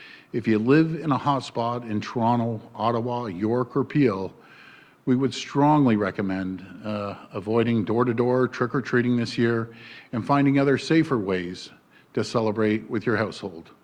“If you live in a hotspot in Toronto, Ottawa, York or Peel, we would strongly recommend avoiding door-to-door trick-or-treating this year and finding other safer ways to celebrate with your household,” said the Premier.